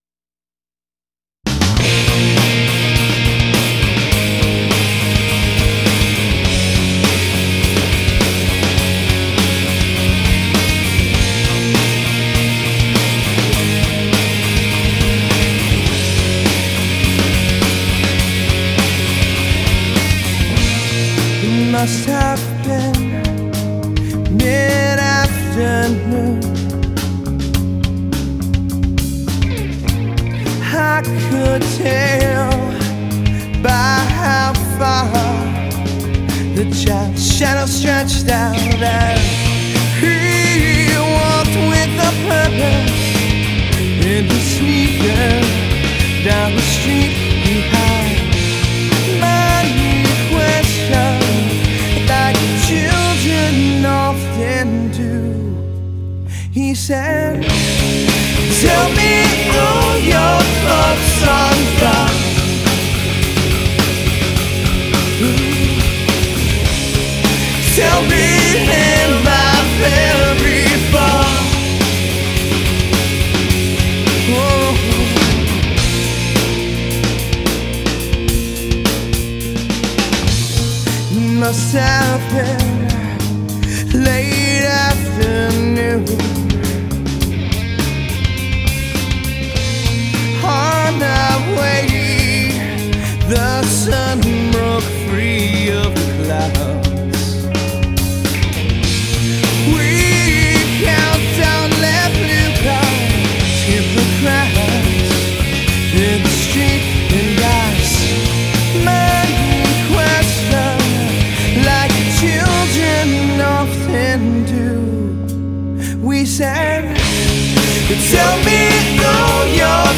Bass
Clear Cut Studios, Saddle Brook, NJ